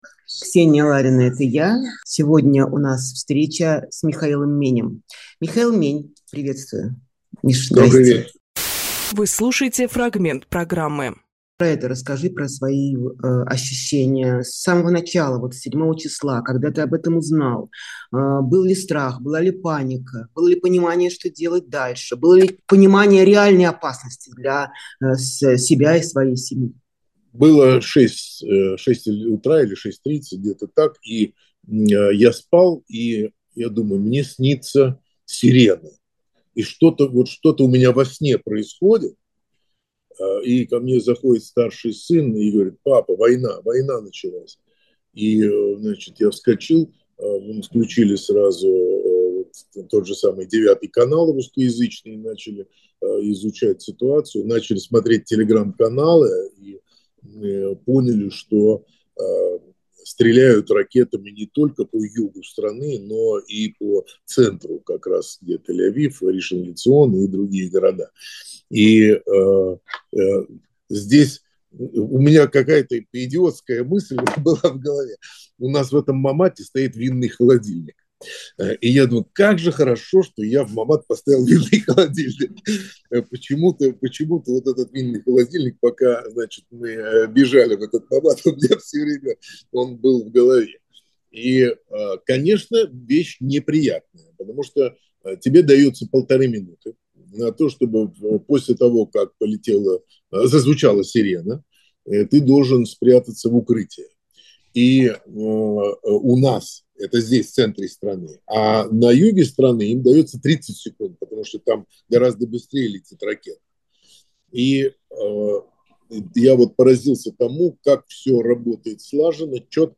Фрагмент эфира от 14.10.23